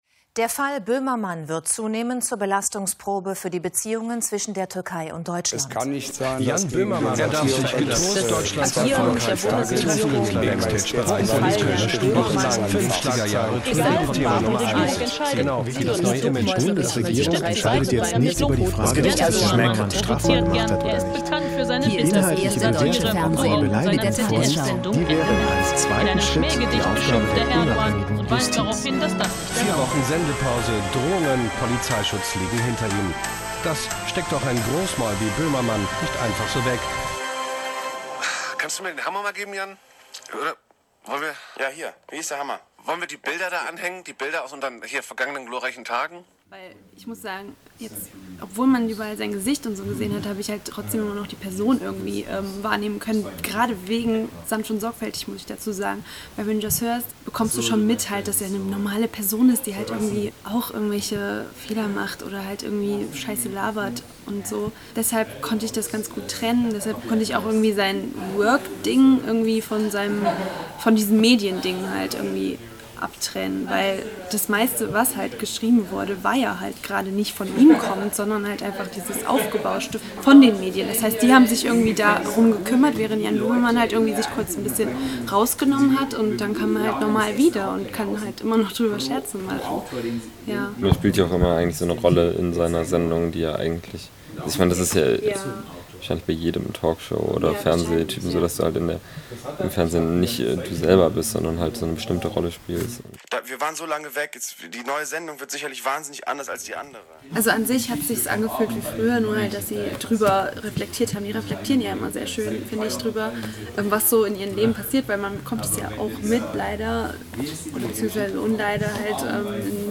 • Mitwirkende: zwei Podcast-Liebende, die Tagesschau vom 11.04.2016 (ARD), ZDF-Beitrag „Jan Böhmermann geht wieder auf Sendung“, „Fest&Flauschig“ vom 16.05.2016